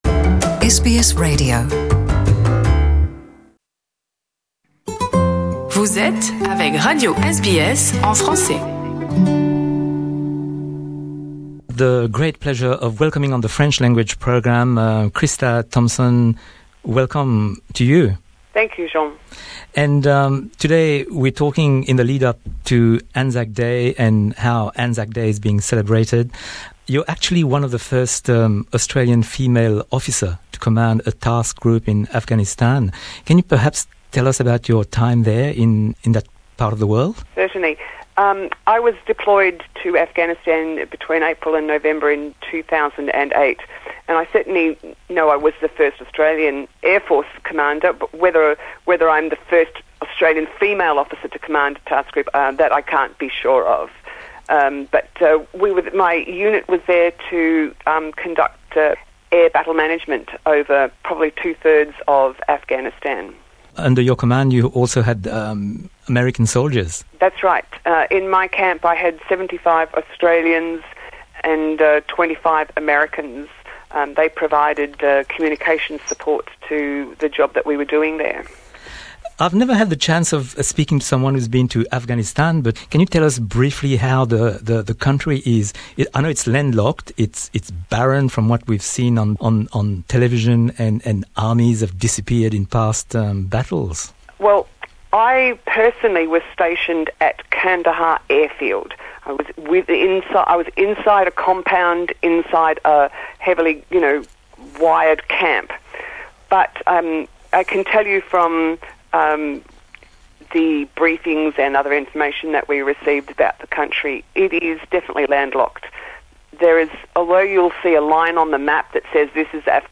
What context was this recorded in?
From the SBS French archives, an interview